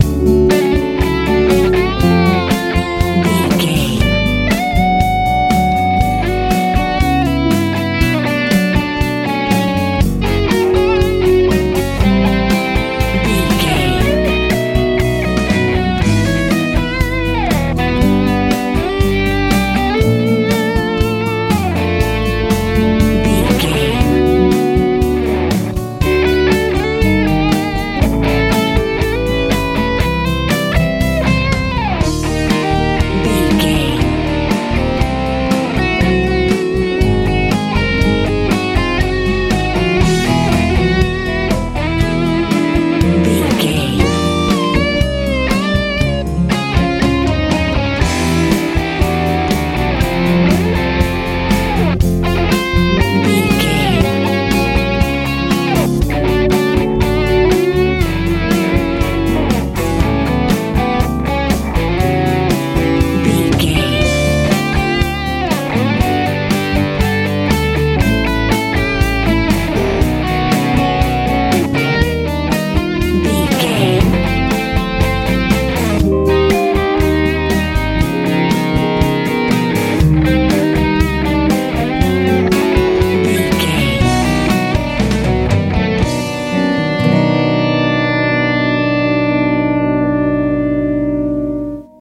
Ionian/Major
groovy
energetic
lively
electric guitar
acoustic guitar
bass guitar
drums
driving